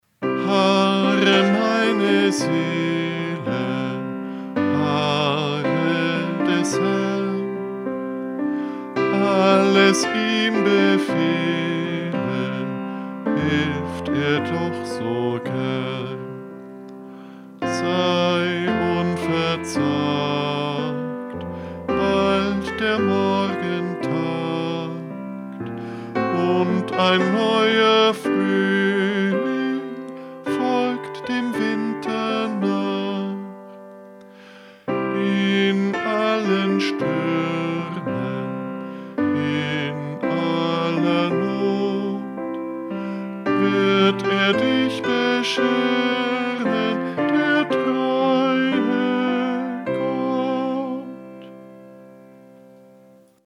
Eingesungen: Liedvortrag (